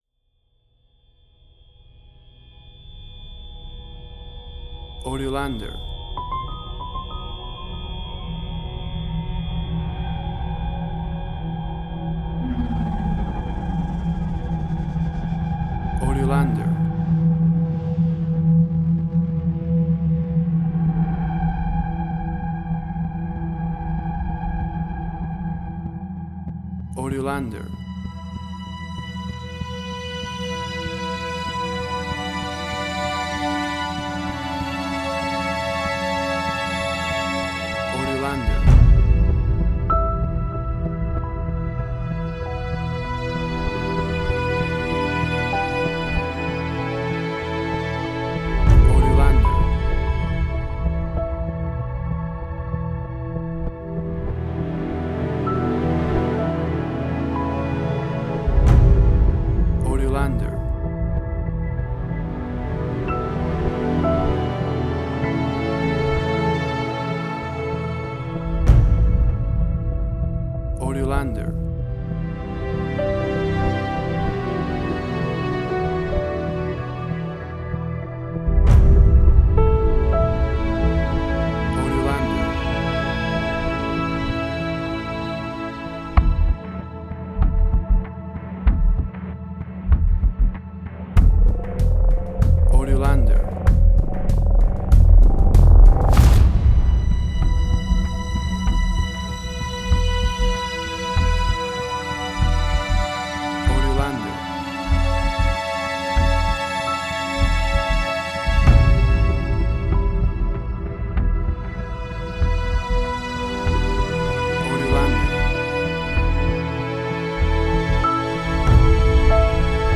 Science fiction, electronic environment.
Tempo (BPM): 95